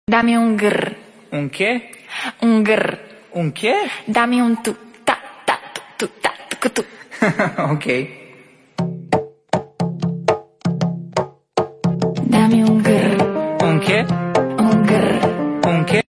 Dame Un Grrr sound effect mp3 download